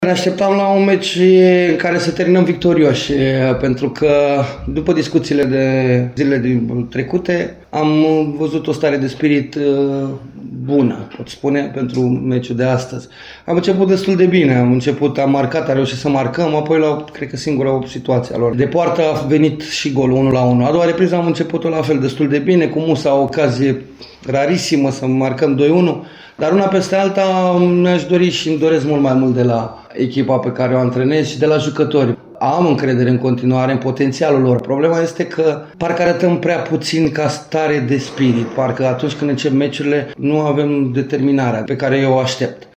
Antrenorul cărășenilor, Flavius Stoican, se aștepta la un rezultat mai bun,ținând cont de starea de spirit ce părea să fie pozitivă înainte de joc: